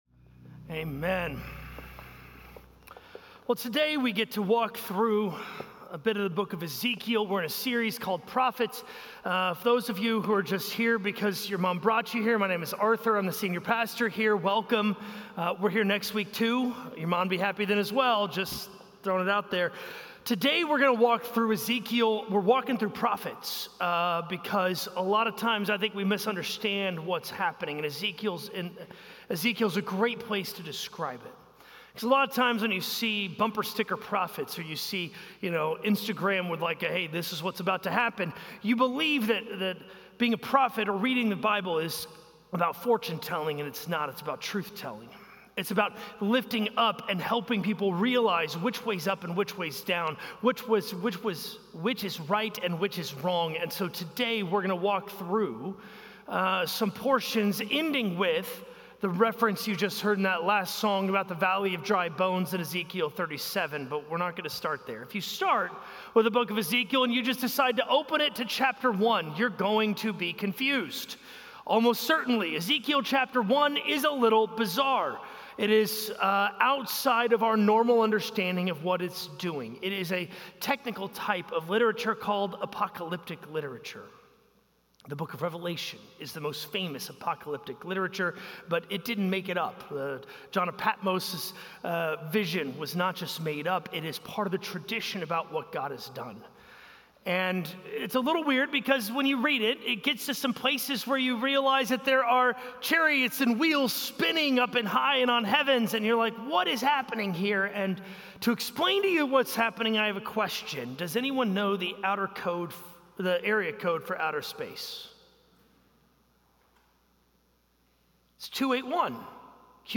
A message from the series "Prophets."